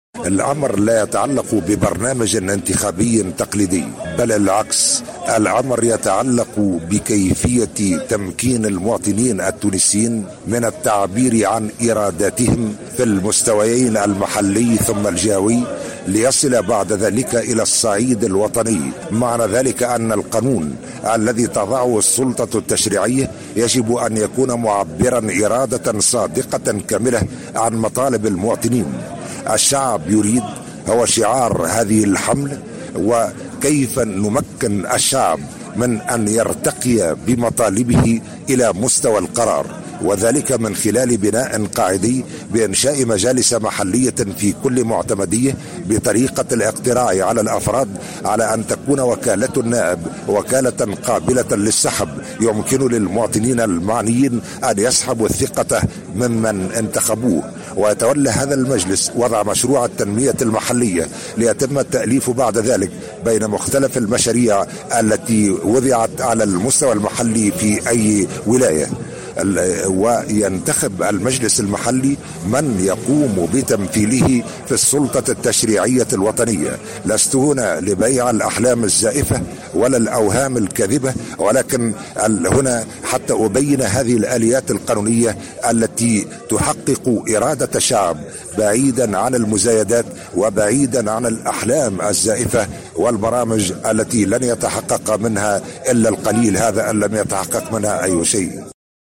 أكد قيس سعيّد المترشح المستقل للانتخابات الرئاسية السابقة لأوانها، خلال زيارة أداها اليوم الجمعة إلى ولاية القيروان، إنه لا يطرح برنامجا انتخابيا تقليديا، وإنما رؤية مغايرة للحكم والتنمية، تمكّن التونسيين من التعبير عن إرادتهم على المستوى المحلي والجهوي، وصولا إلى المستوى المركزي.